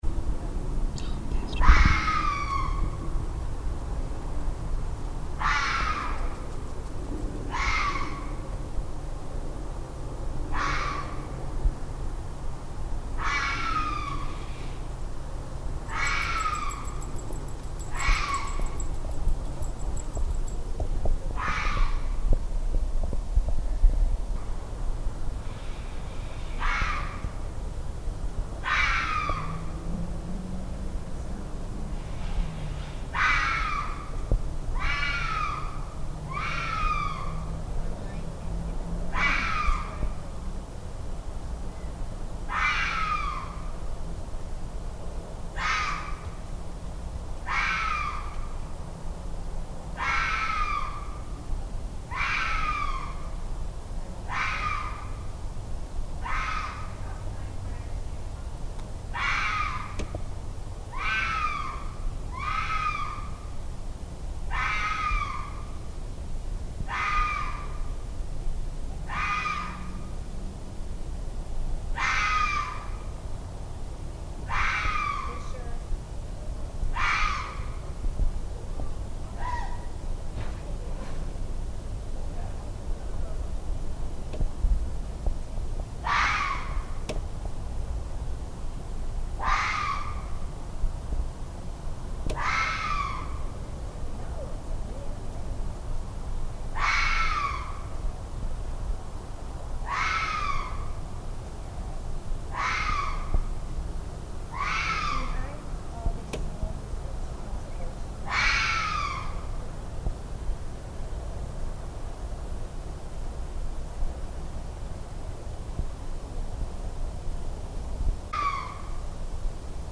There was one very close calling so I stuck my shotgun mic out the front door.
Ooh, definitely a male and a female calling to each other.
FoxCombined.mp3